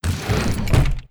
rust-rl/resources/sounds/door/close1.wav at be7ff6b8959afeffd3f96f687d12676adba2c2f1
door sounds